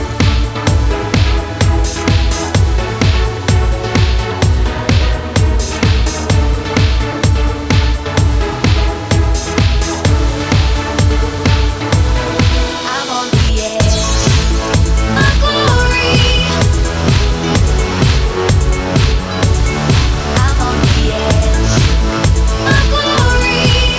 Two Semitones Down Pop